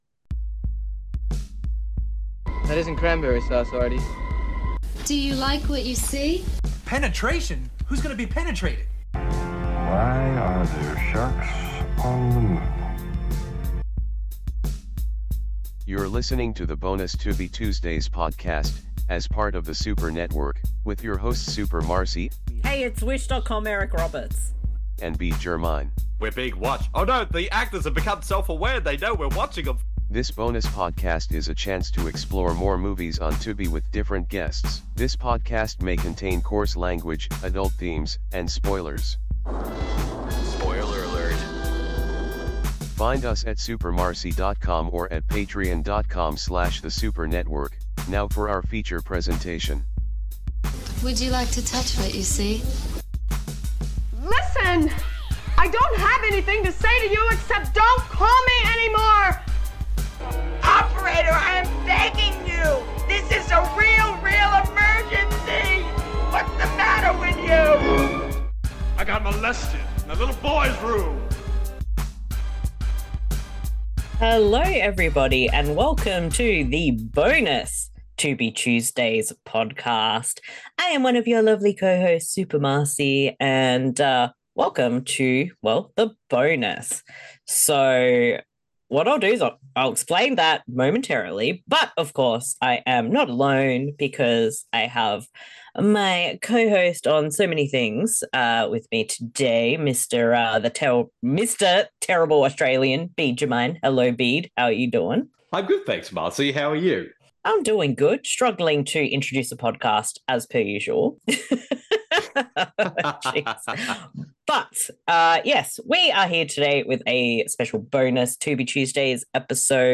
Much like with Tubi Tuesdays the podcast is focused on discovering and doing commentaries/watch a longs for films, the more random and weird the better!